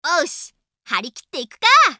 System Voice